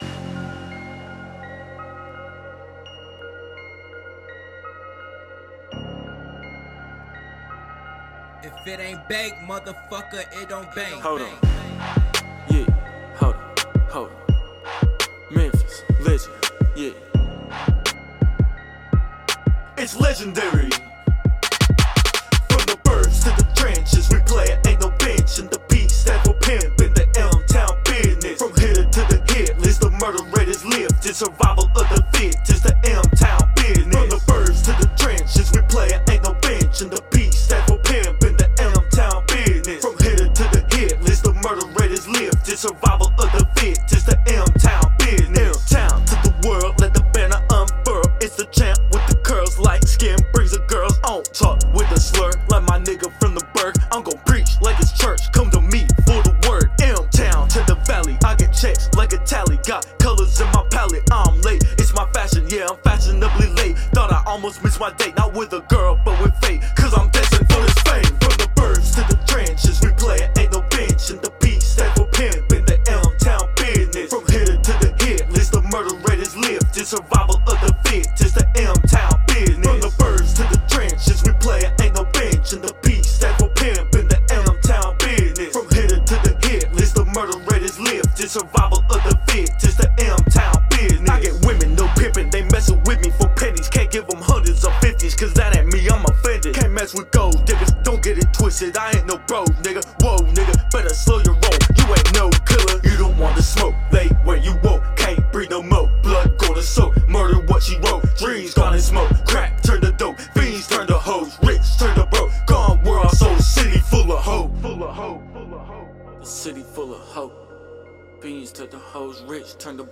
Rap(Music), Hip-hop, Popular music—Tennessee—Memphis, African Americans—Music, Memphis(Tenn.)—Social life and customs